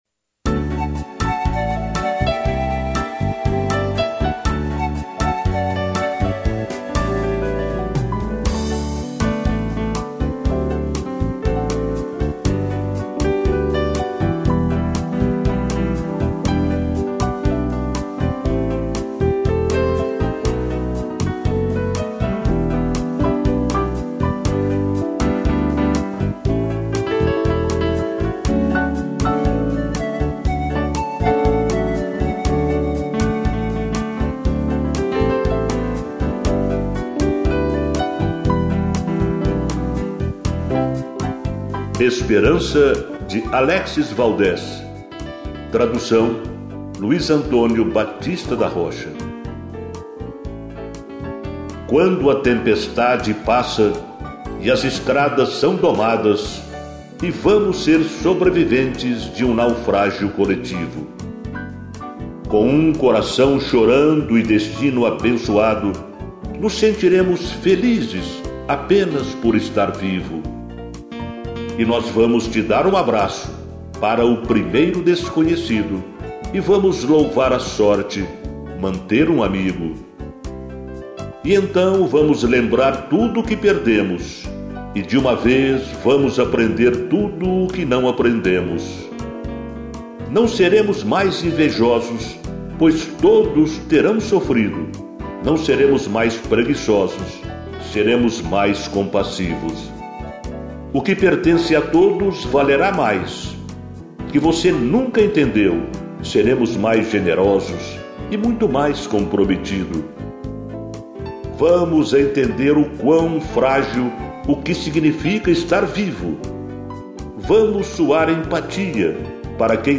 piano e flauta pan